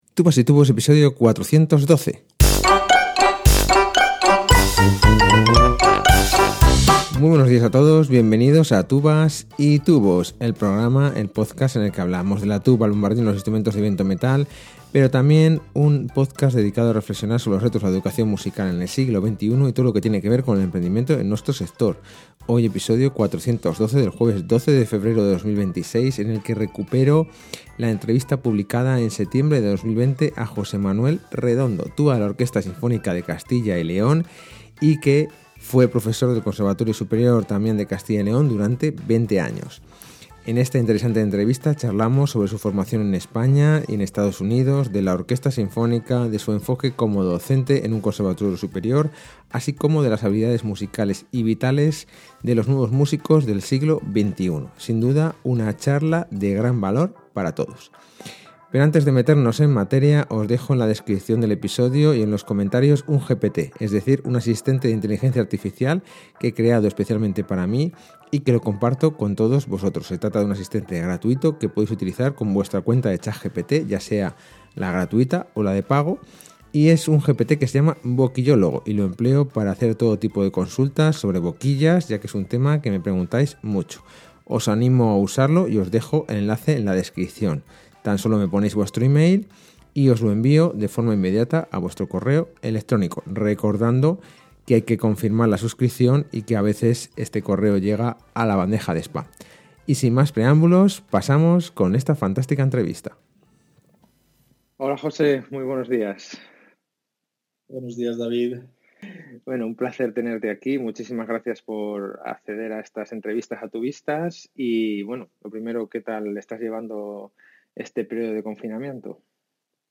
Recupero entrevista